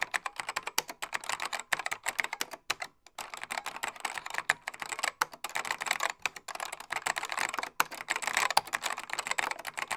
Index of /90_sSampleCDs/AKAI S6000 CD-ROM - Volume 6/Tools/KEYBOARD_TYPING
KEY TYPE 3-S.WAV